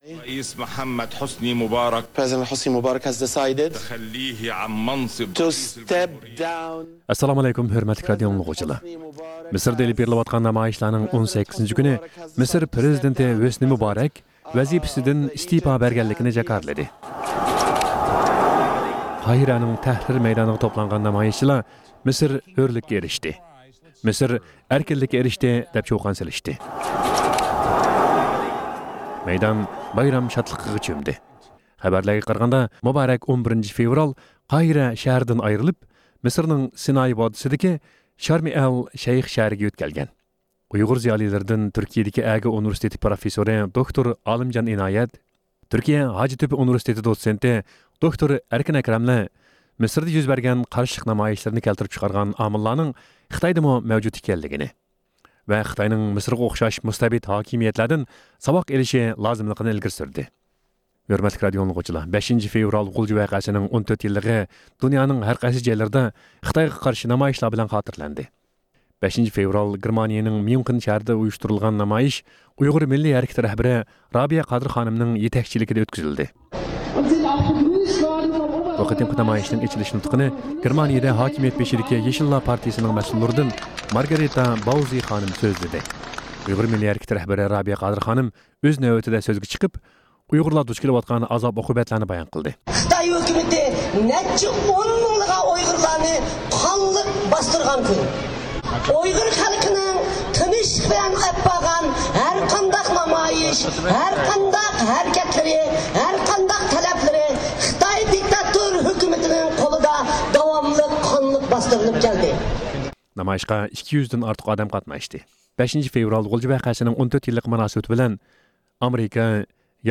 ھەپتىلىك خەۋەرلەر (5-فېۋرالدىن 11-فېۋرالغىچە) – ئۇيغۇر مىللى ھەركىتى